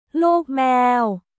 ルーク・メーオ